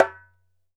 ASHIKO 4 0KL.wav